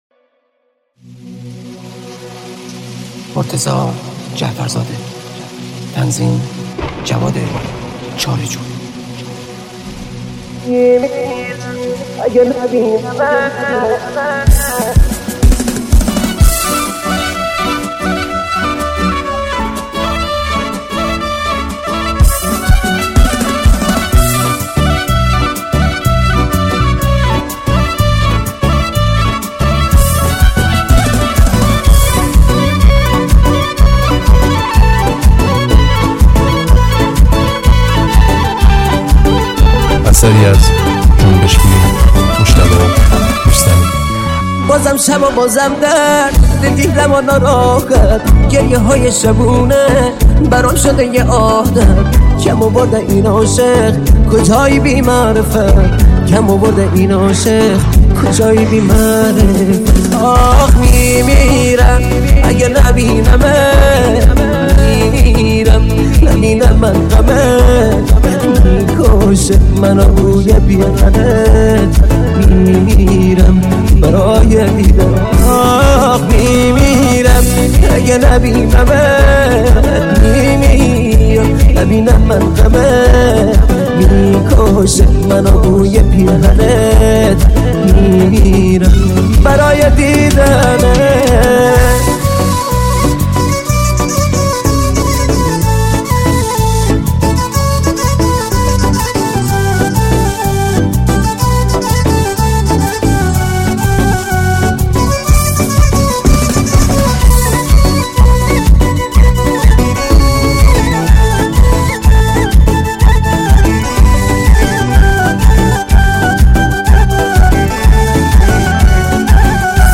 ریمیکس بیس دار تند
Persian Pop Song
پخش آنلاین و دانلود نسخه ریمیکس: